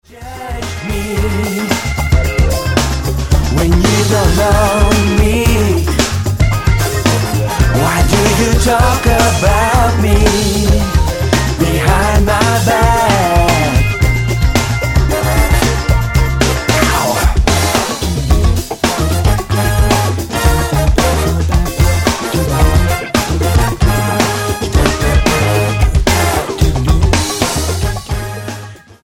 Swiss-Serbian blue eyed soul gospel singer
Style: R&B